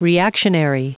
Prononciation du mot reactionary en anglais (fichier audio)
Prononciation du mot : reactionary